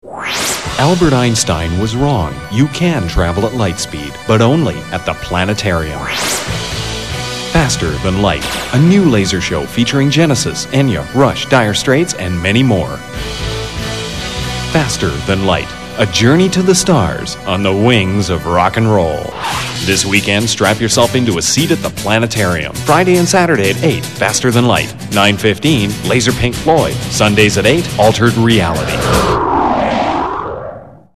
FTL Commercial
All of the above audition examples were written and/or Voiced here.